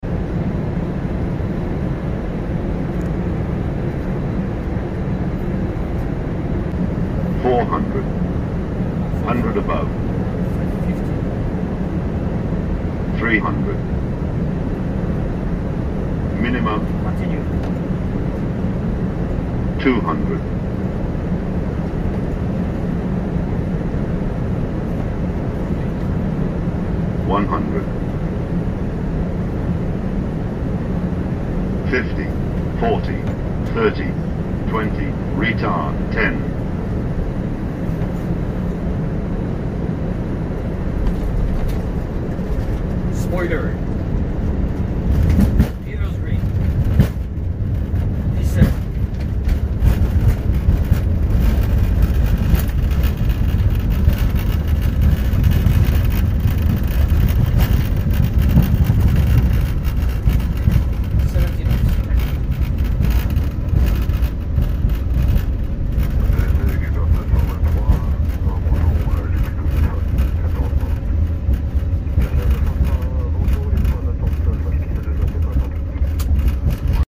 A350 100 Manually Flown Approach into sound effects free download
A350-100 Manually Flown Approach into Pointe-à-Pitre